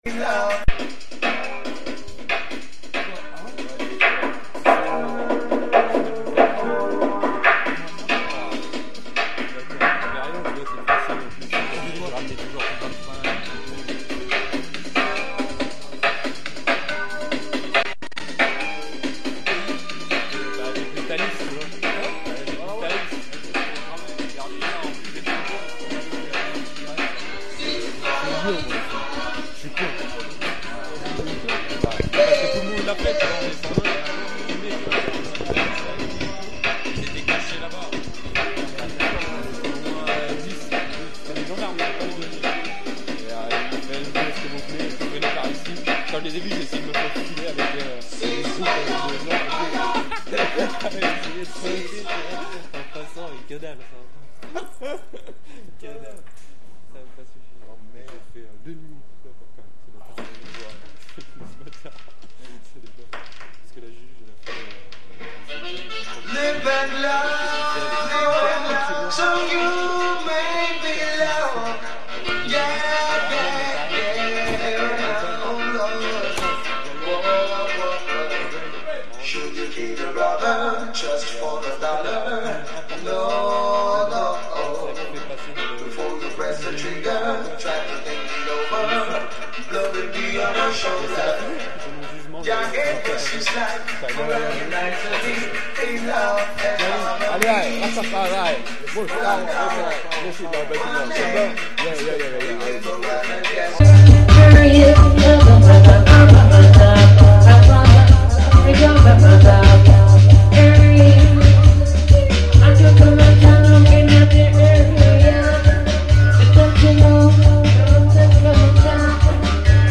A ST DENIS